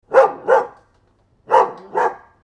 dog bark type 06 Sound Button: Unblocked Meme Soundboard
Dog Sounds